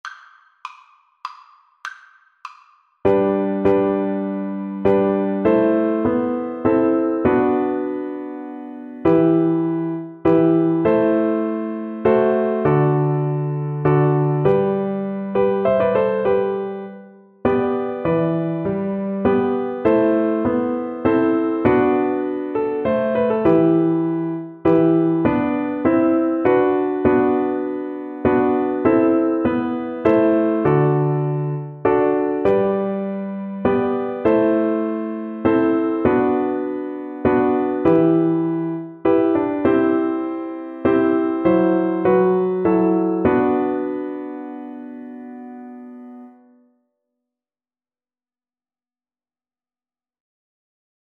Flute
3/4 (View more 3/4 Music)
G major (Sounding Pitch) (View more G major Music for Flute )
Con moto
Traditional (View more Traditional Flute Music)
world (View more world Flute Music)